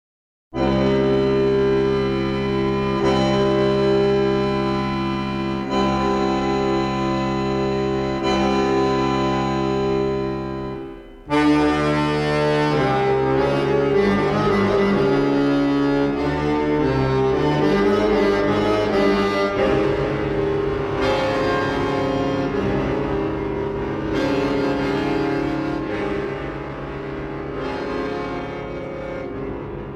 for bayan solo